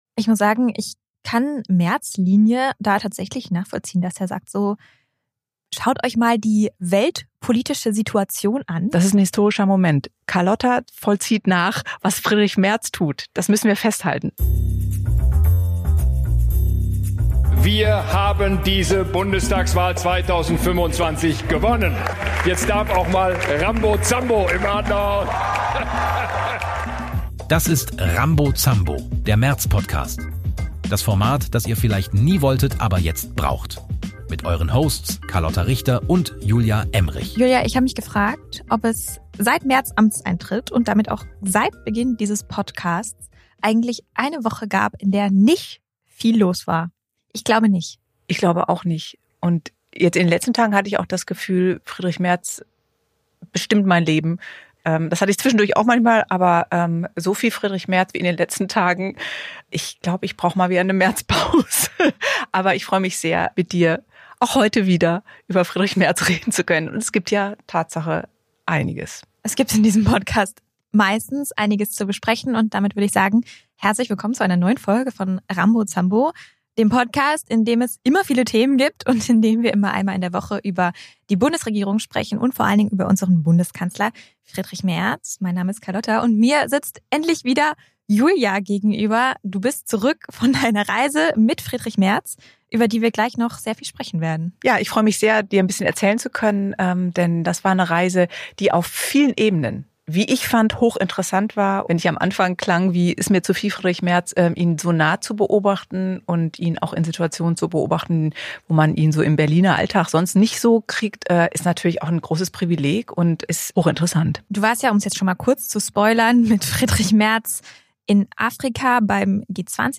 Zwei Frauen.